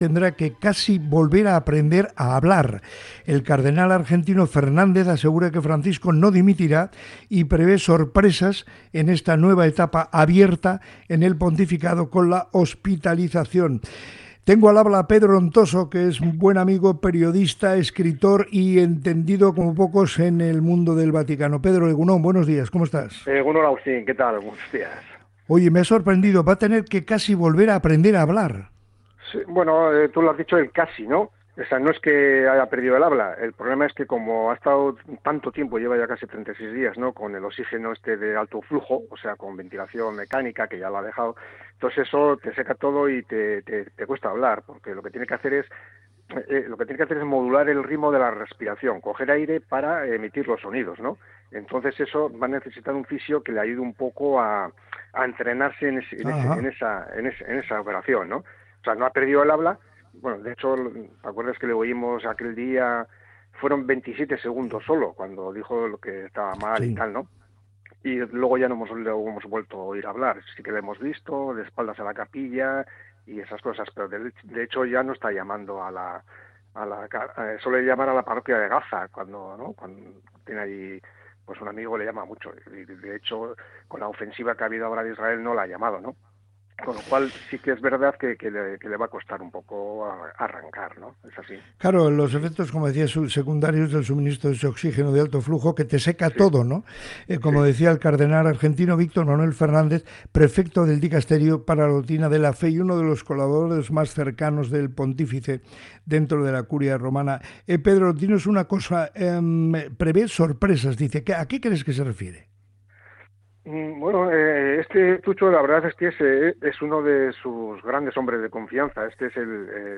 En el programa Moliendo Café de Radio Popular – Herri Irratia hemos hablado con el periodista y escritor